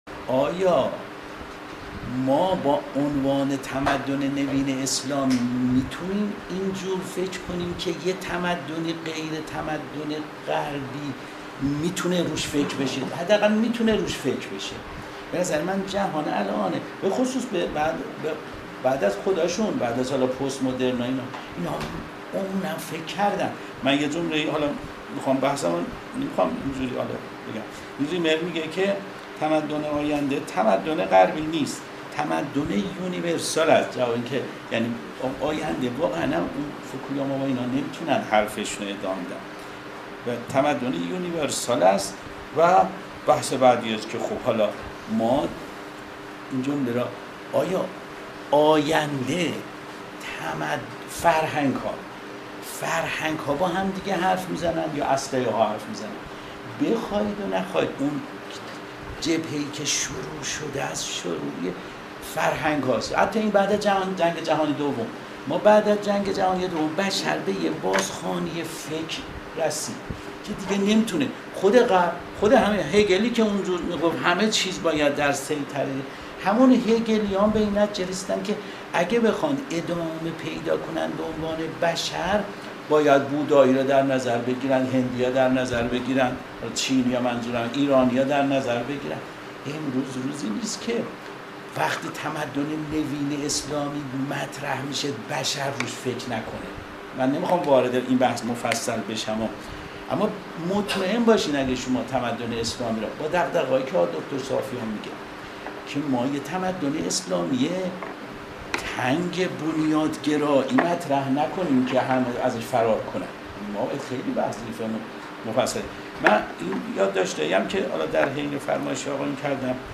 سخنان
درسیزدهمین جلسه دعوت به اندیشه درخانه بیداری اسلامی اصفهان